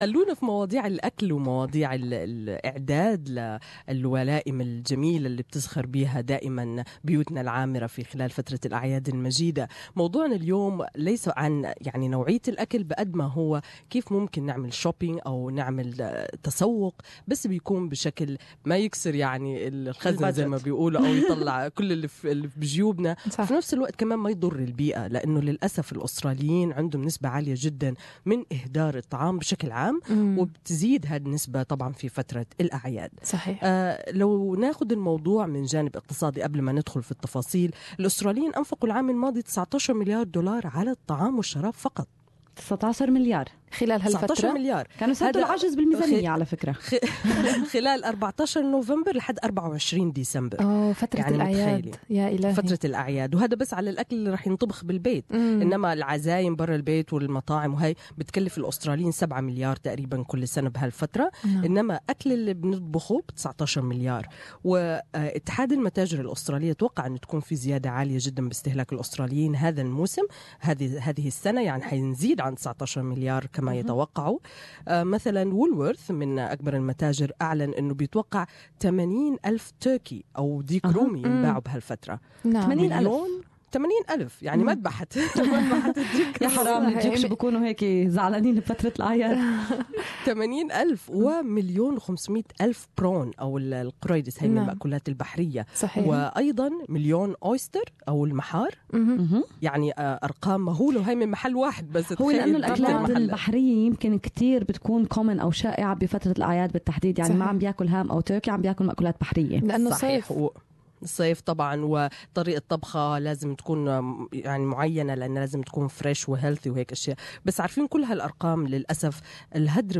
More in this interview on how to budget for a great feast without breaking the bank!